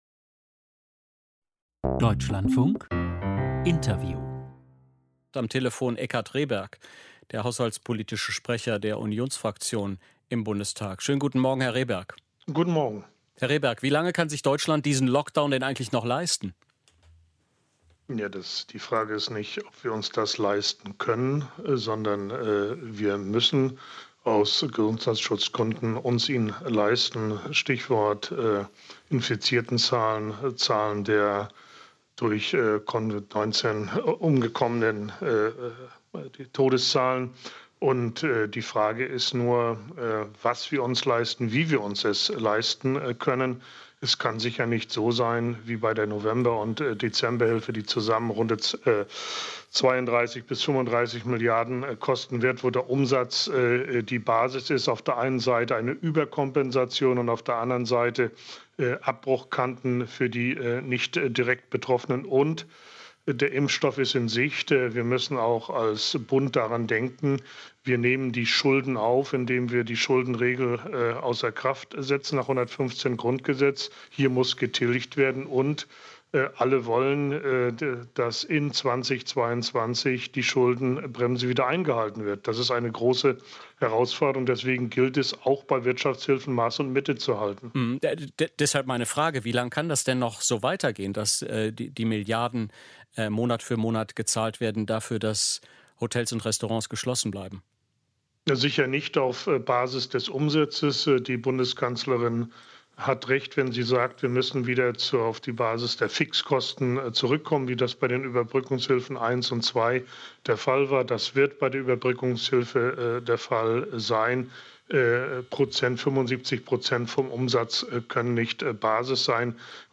Vorbericht